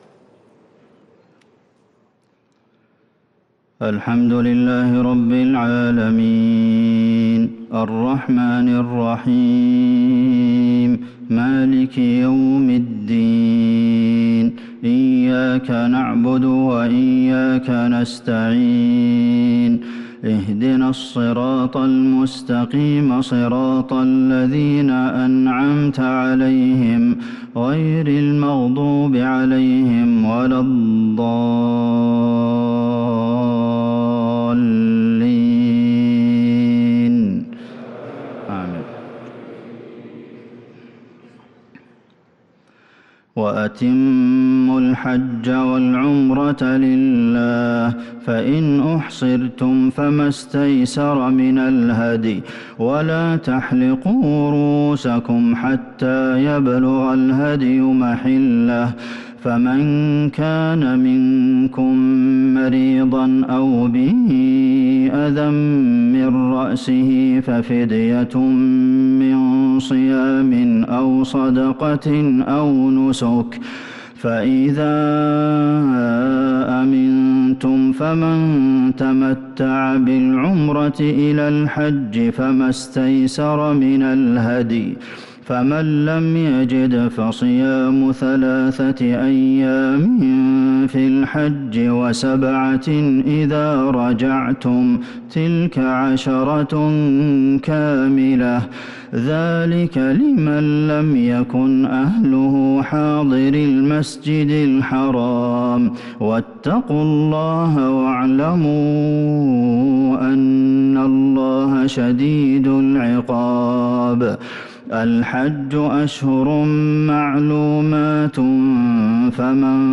صلاة العشاء للقارئ عبدالمحسن القاسم 7 ذو الحجة 1443 هـ
تِلَاوَات الْحَرَمَيْن .